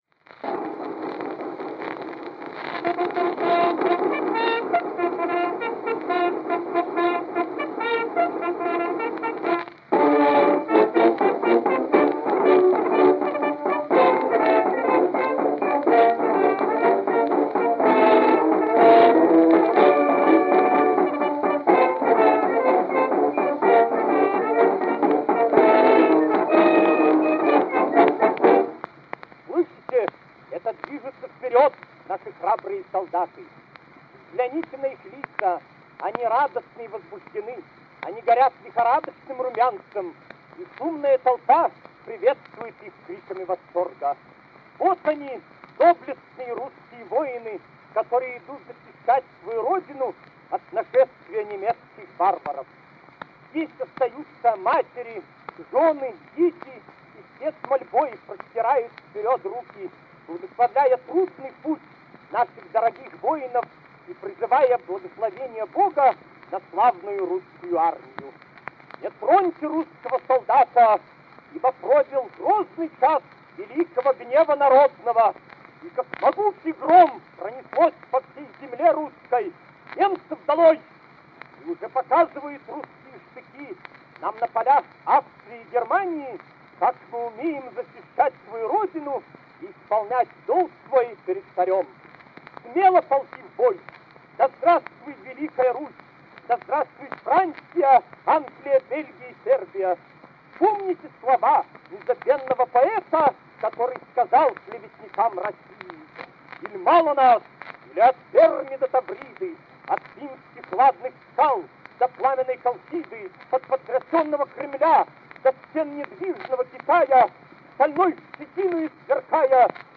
грамзапись 1914 года
Патриотическая сцена